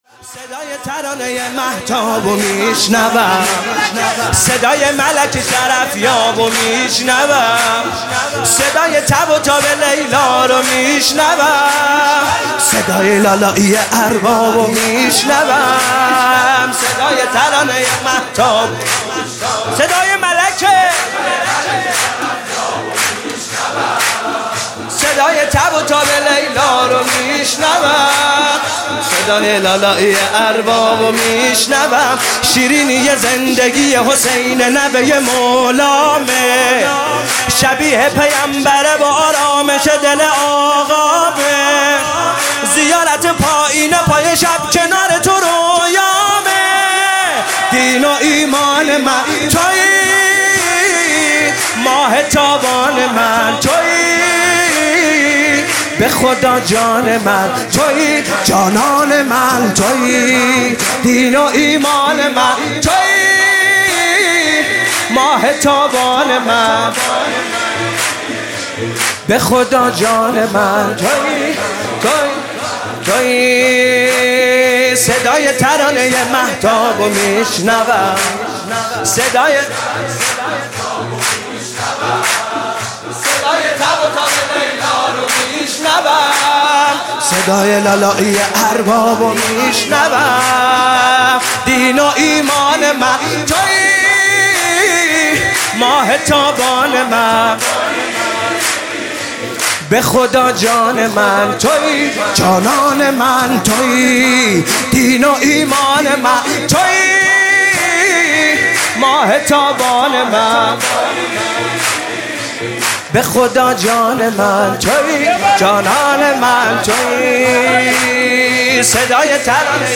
سرود: صدای ترانه ی مهتاب می شنوم